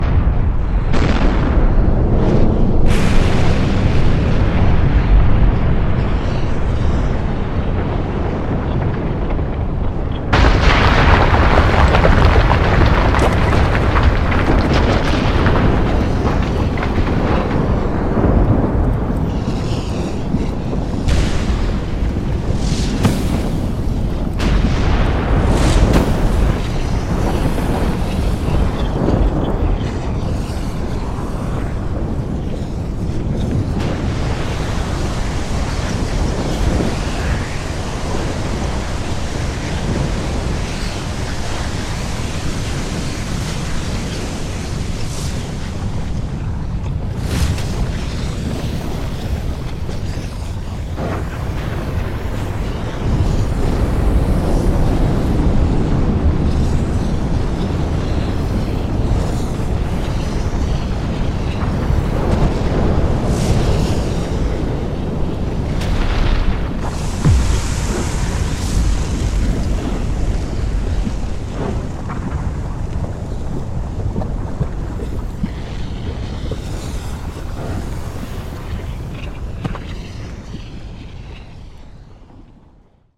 volcano_eruption.mp3